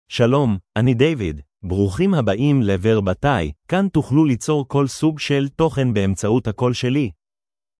David — Male Hebrew (Israel) AI Voice | TTS, Voice Cloning & Video | Verbatik AI
MaleHebrew (Israel)
Voice sample
Listen to David's male Hebrew voice.
David delivers clear pronunciation with authentic Israel Hebrew intonation, making your content sound professionally produced.